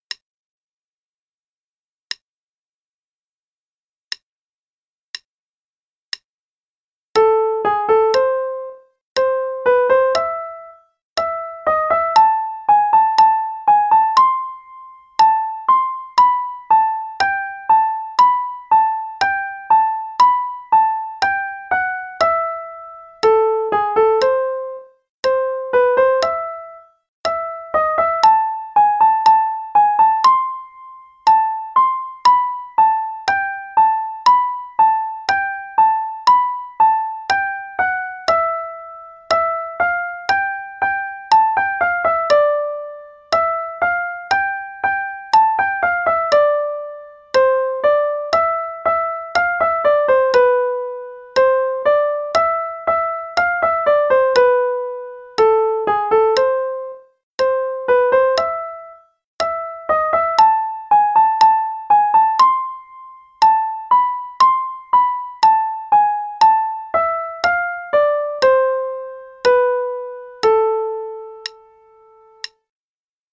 Rondo alla Turca (RH only, qn=60)
Play-along_Mozart - Rondo alla Turca (RH only, qn=60).mp3